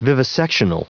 Prononciation du mot : vivisectional
vivisectional.wav